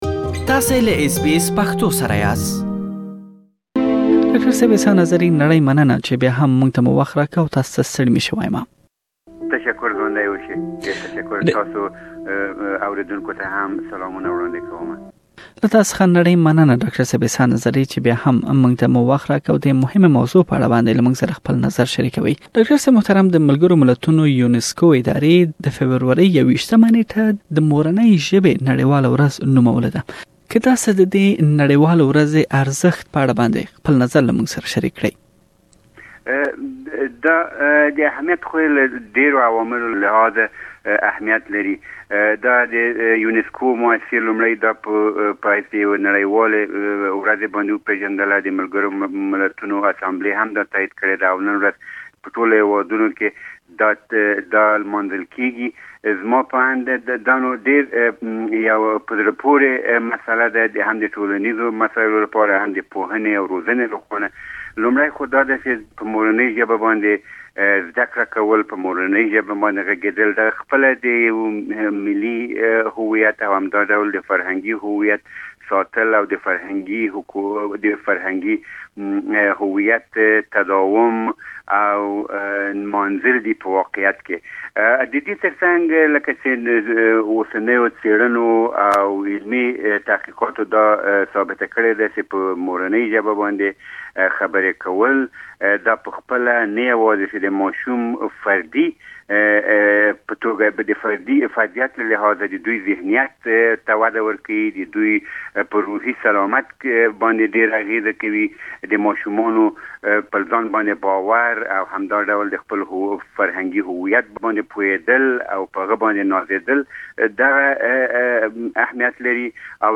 بشپړه مرکه